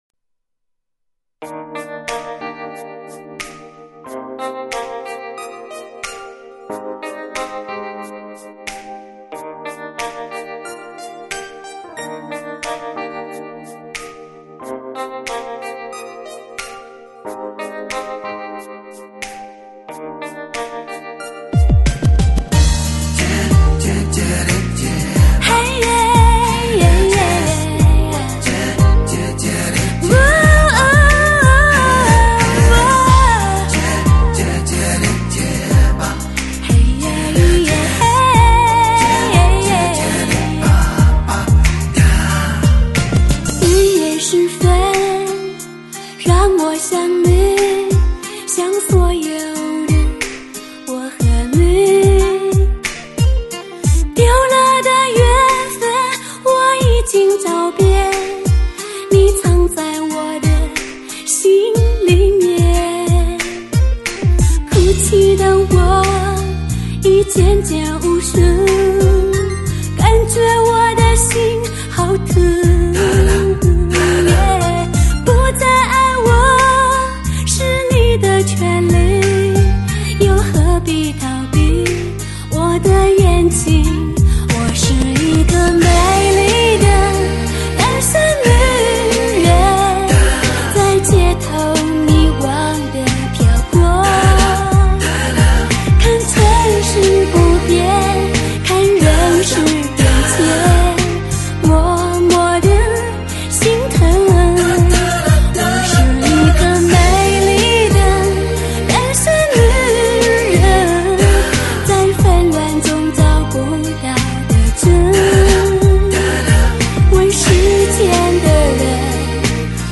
透明清澈的歌声在浑厚浊重的音乐中飞翔......
坐沙发，听动情女声。
动情女声